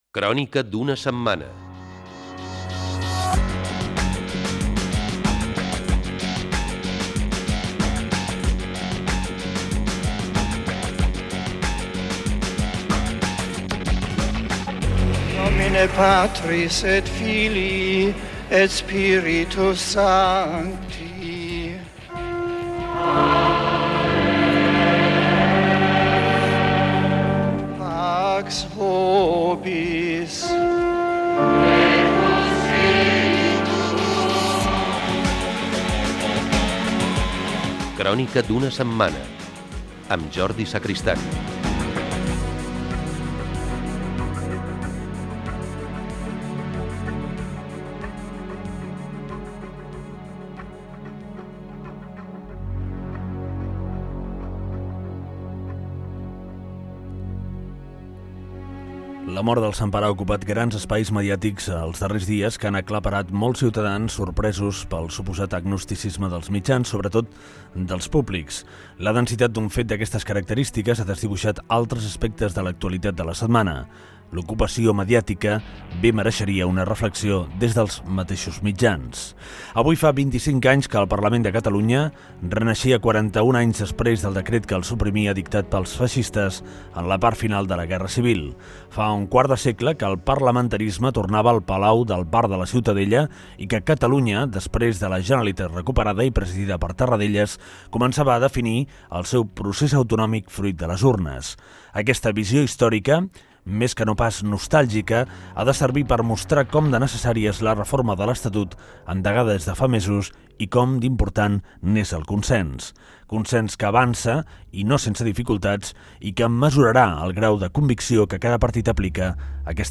b6da3b382b69b744dea7c8dc6ffdc23b7c68f10e.mp3 Títol COM Ràdio Emissora COM Ràdio Barcelona Cadena COM Ràdio Titularitat Pública nacional Nom programa Crònica d'una setmana Descripció Indicatiu del programa, rseum informatiu de la setmana. Mort del Papa Joan Pau II, repàs a les notícies del 10 d'abril de 1980 i entrevista al president del Parlament, Ernest Benach